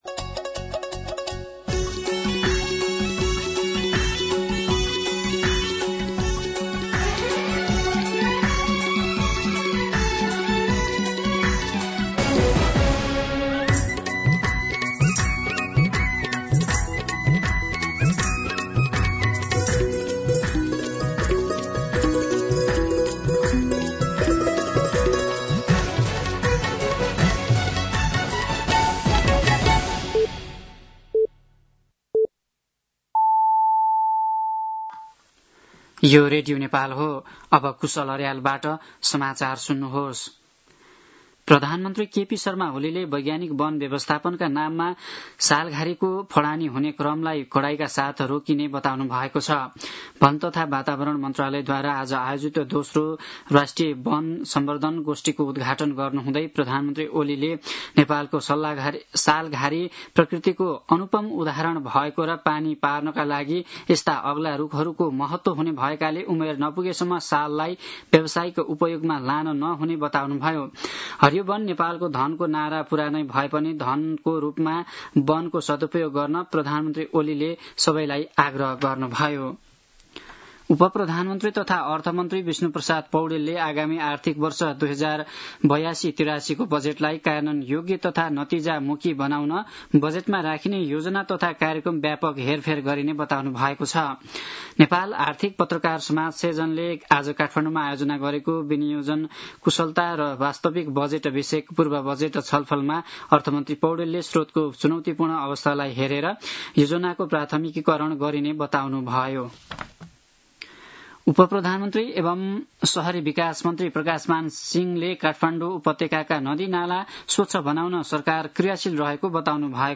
दिउँसो ४ बजेको नेपाली समाचार : २७ वैशाख , २०८२